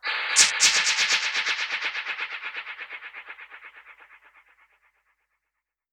Index of /musicradar/dub-percussion-samples/125bpm
DPFX_PercHit_B_125-06.wav